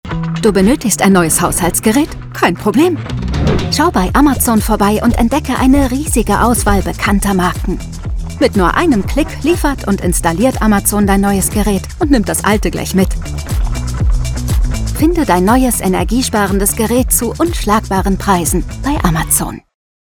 Sprecherin für Werbung, Imagefilme, Hörbücher, Dokumentationen und Co.
Sprechprobe: Werbung (Muttersprache):
01_Werbung_Amazon_Deutschland.mp3